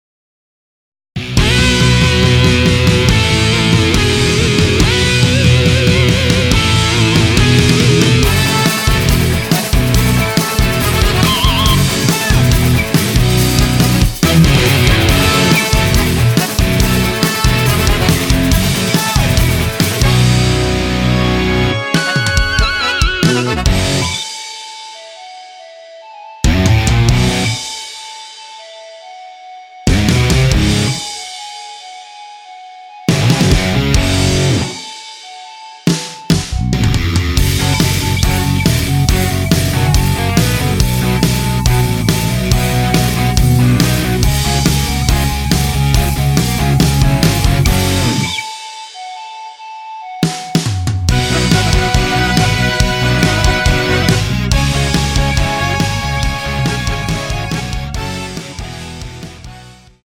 원키에서(-2)내린 멜로디 포함된 MR입니다.
F#
앞부분30초, 뒷부분30초씩 편집해서 올려 드리고 있습니다.
중간에 음이 끈어지고 다시 나오는 이유는